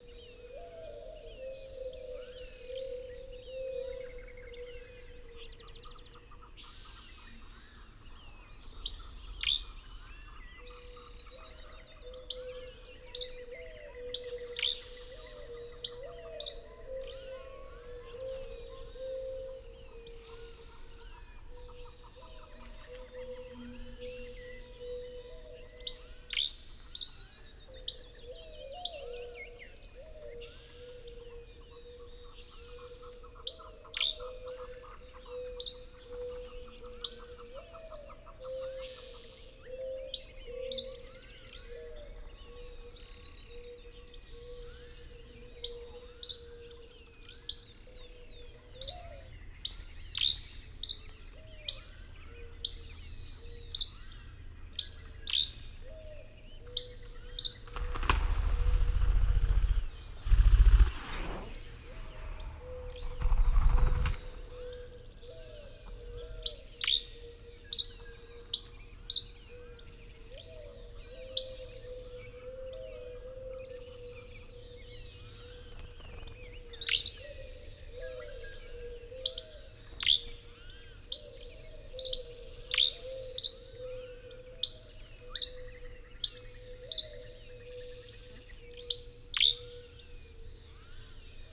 CANYON AMBIX
CANYON-AMBIX.wav